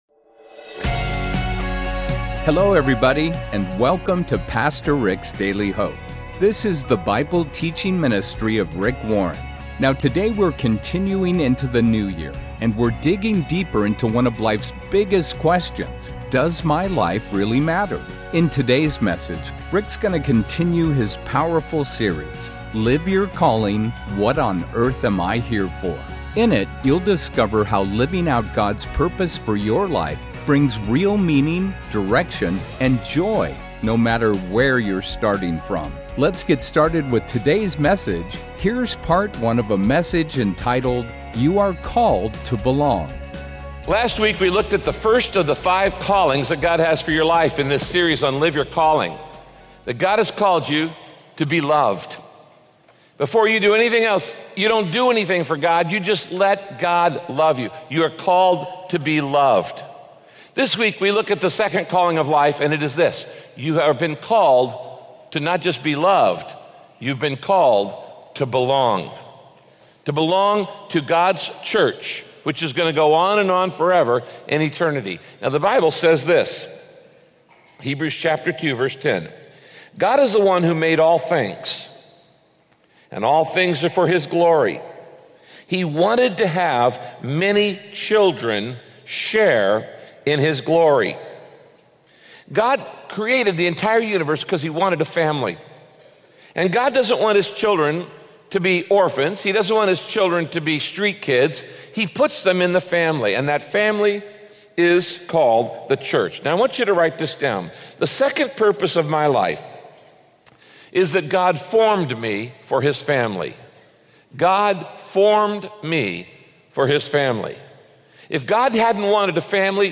In this message, Pastor Rick explains why being part of a community of believers is so important to your spiritual walk and why we need to recover and practice the biblical meaning of membership.